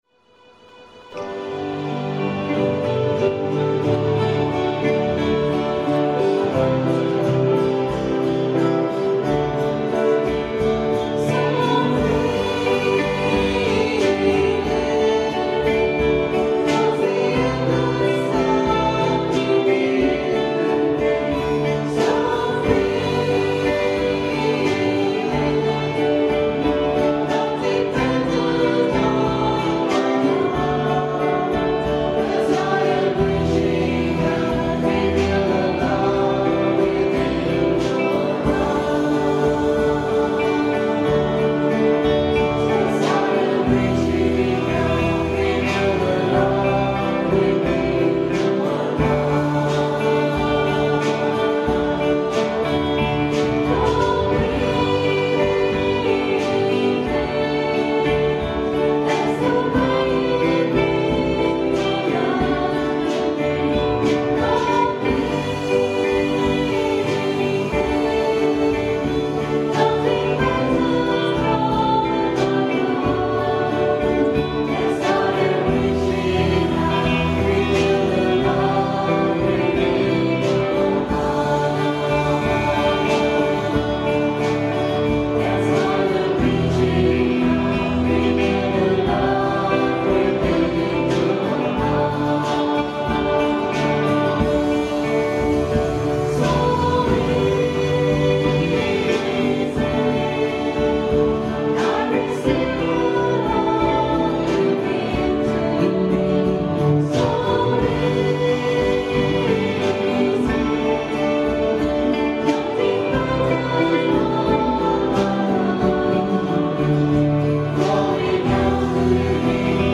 Recorded (in digital stereo on minidisc) at 10am Mass on Sunday, 19th October, 2003.